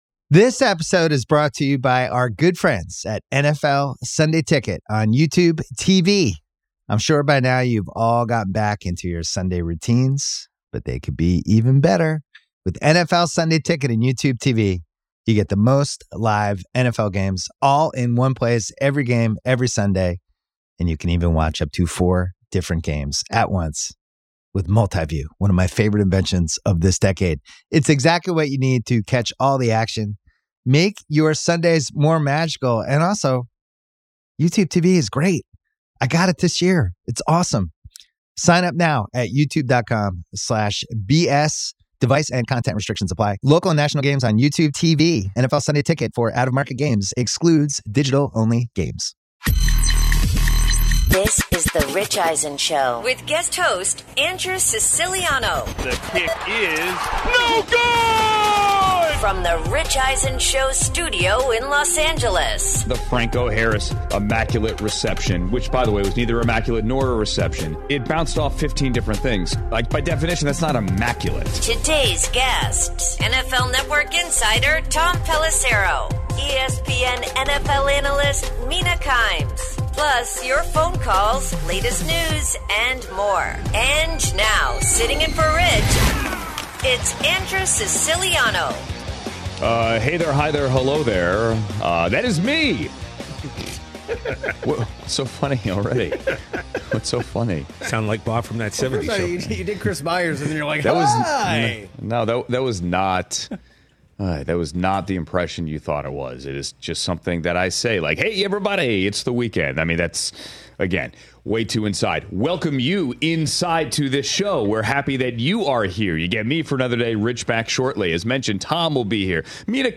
Guest host Andrew Siciliano and the guest discuss the latest developments/lack thereof on the Brandon Aiyuk trade market and other happenings around NFL training camps.